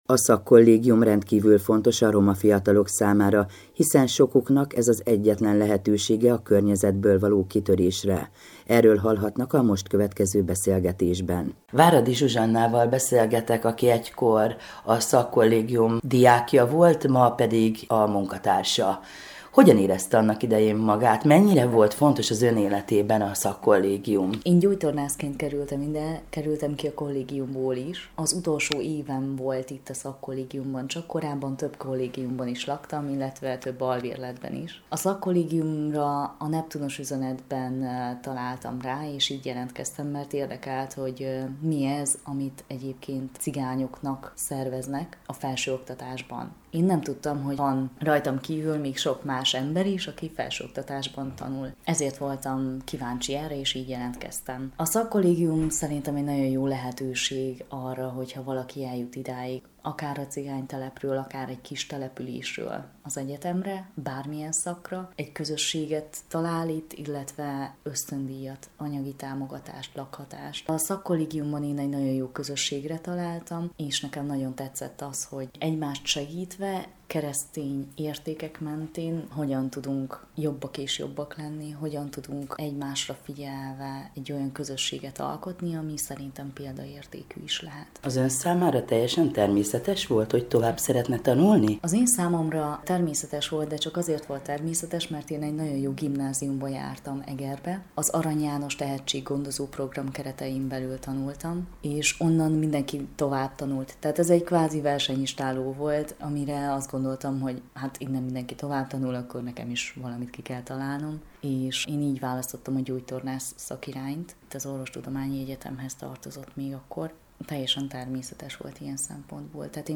A szakkollégium rendkívül fontos a roma fiatalok számára, hiszen sokuknak ez az egyetlen lehetősége a környezetből való kitörésre. Erről hallhatnak a most következő beszélgetésben.